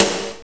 NJS SNR 17.wav